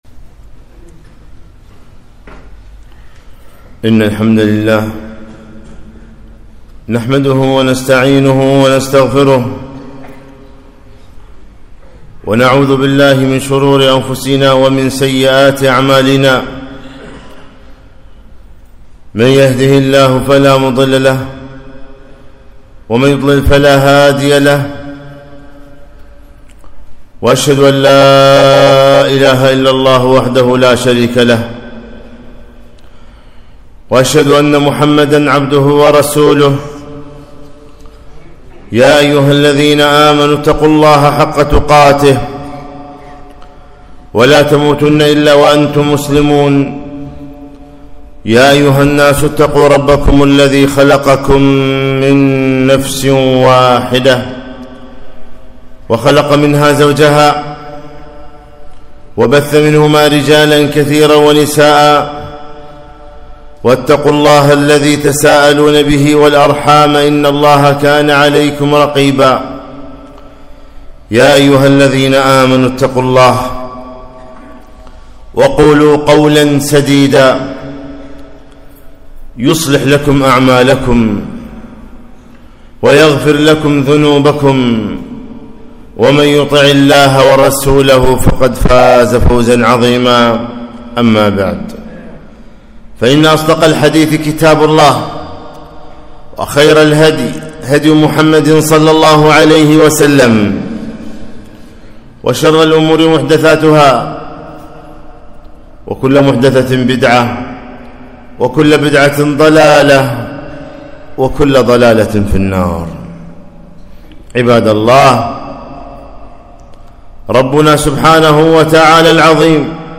خطبة - ( وما قدروا الله حق قدره )